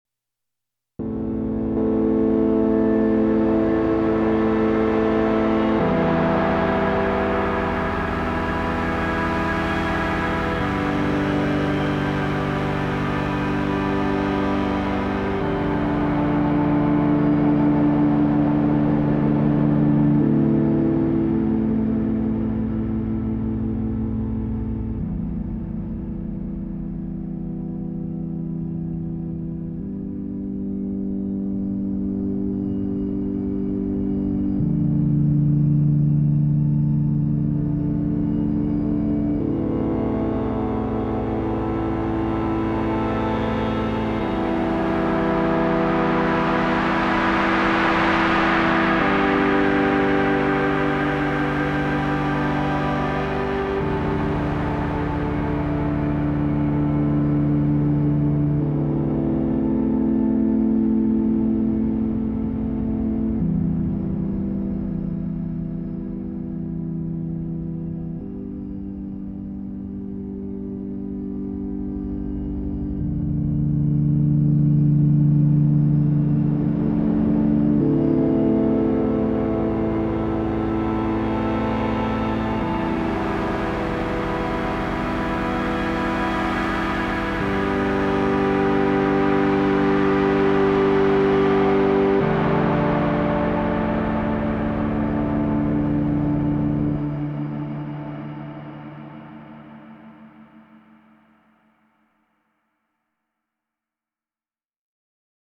You can Pan the 3 Oscillators, the Noise and the Ring separately.
It creates a lot of movements in one Patch.
These are the last 2 Patches I did on the Hydrasynth :
(The Patches pass through the CXM 1978 Reverb)